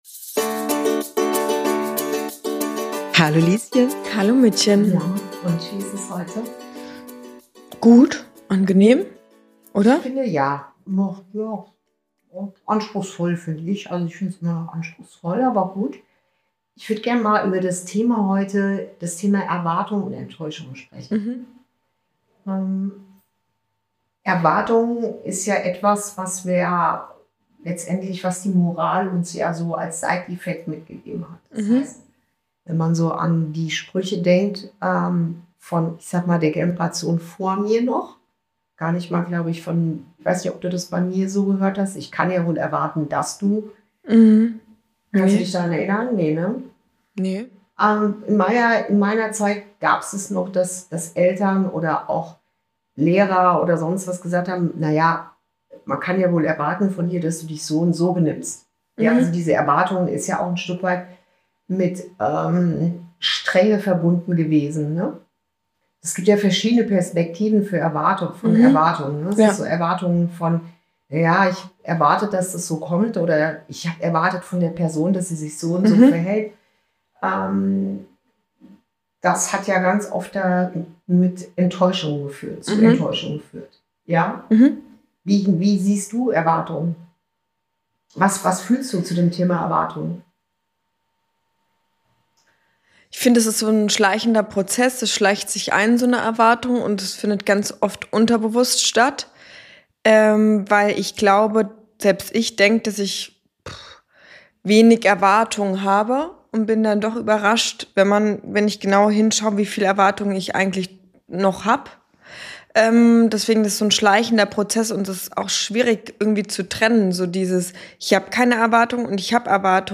Ein Gespräch zwischen Mutter und Tochter